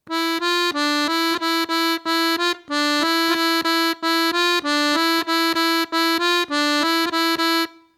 Bulgarian Accordion Tutorial - Lesson 3
Bulgarian dance music is (almost always) played legato.
Audio (slow)